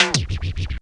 TIMBALE SCRA.wav